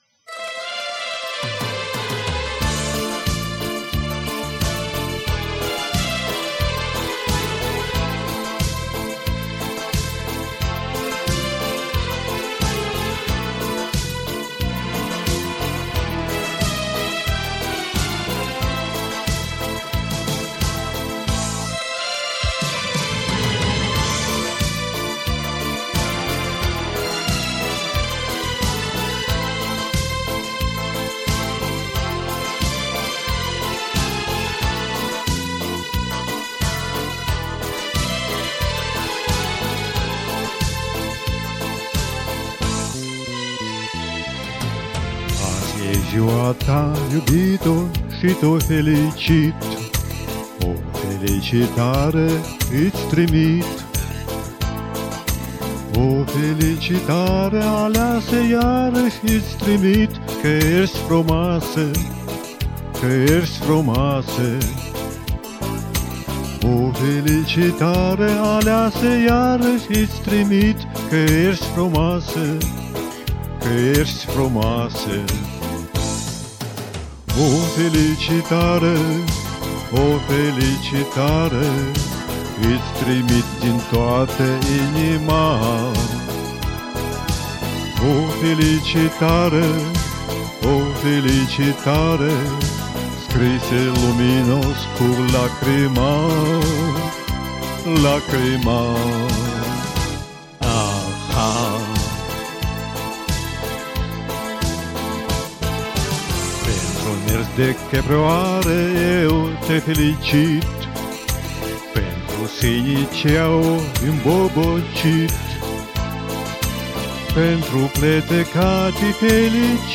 Нет полета, в нотках недотяг...